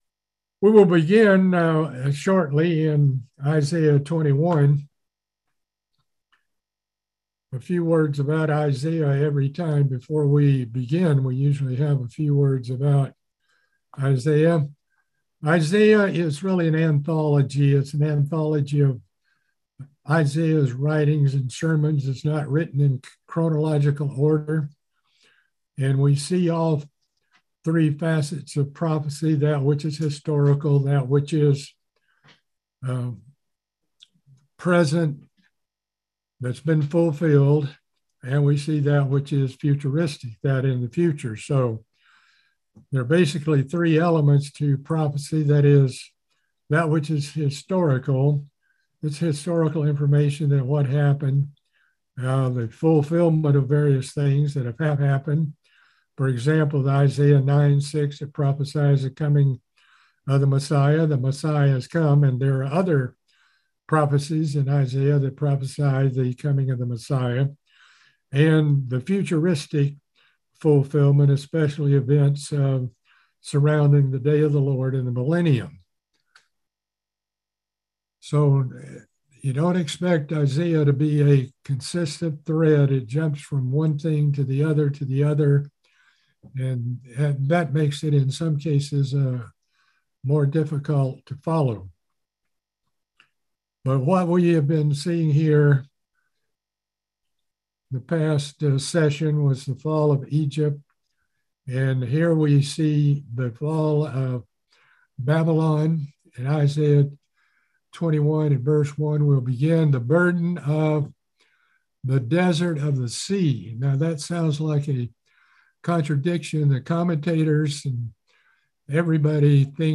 Book of Isaiah Bible Study - Part 16